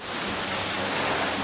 hose.ogg